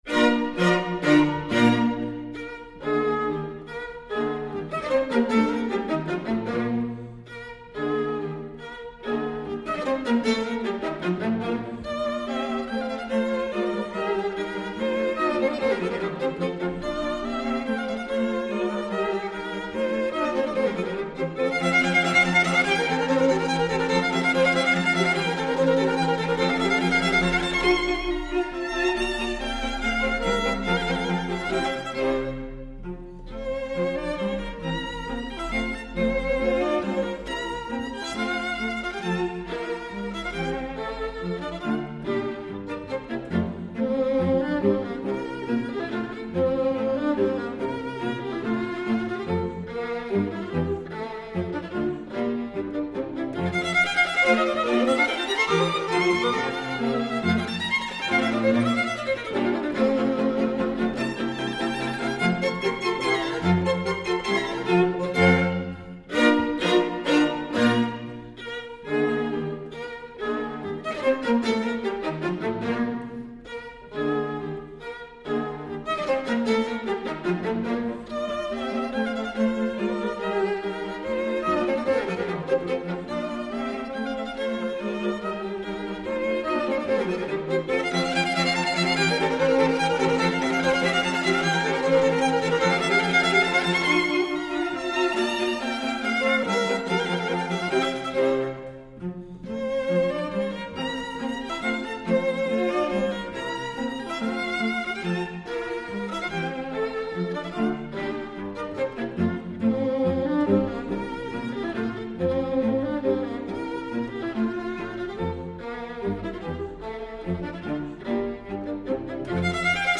String Quartet in B flat major
Allegro spiritoso